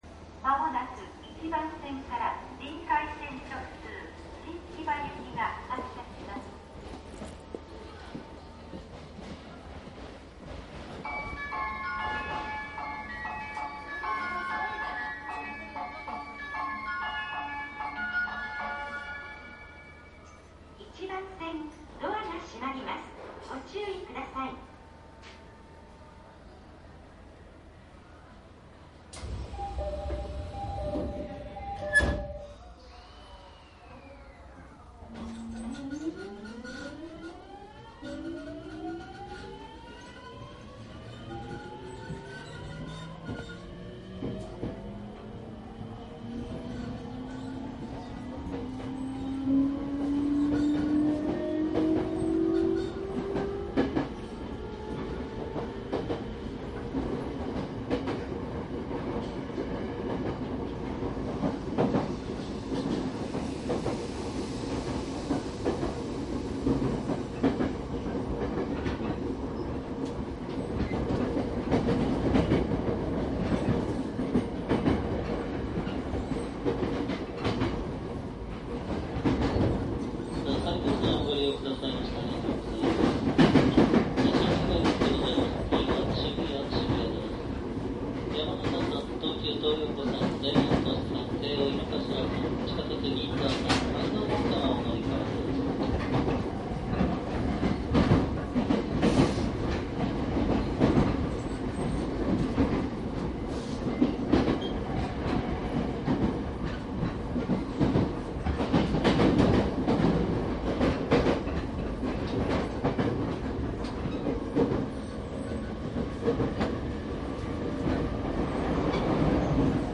♪りんかい線電車 走行音 ＣＤ♪
りんかい線 で主に上り方面を録音したCDです。
■【各駅停車】赤羽→大崎／大崎→新木場 70－061→091＜DATE02-12-02＞
DATかMDの通常SPモードで録音（マイクＥＣＭ959）で、これを編集ソフトでＣＤに焼いたものです。